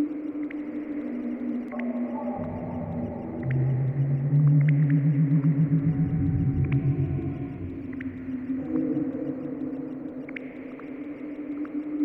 amb_loop.wav